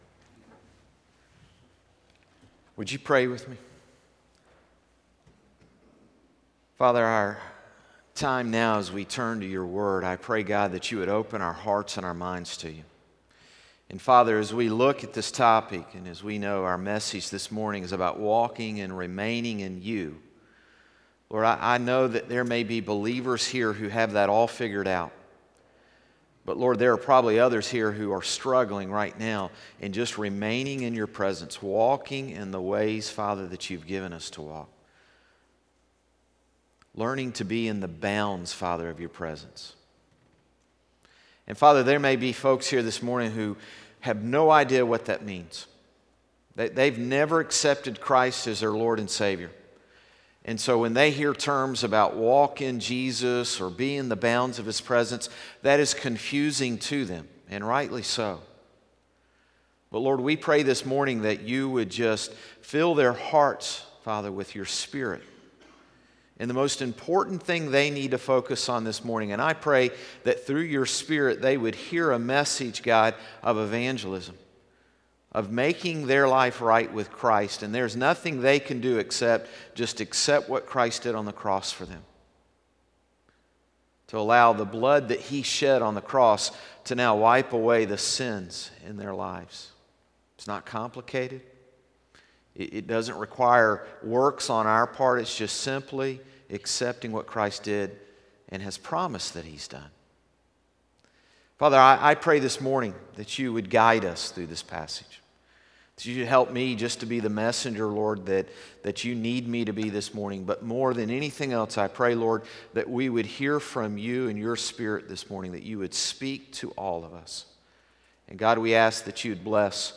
Sermons - Concord Baptist Church
Morning-Service-3-24-19.mp3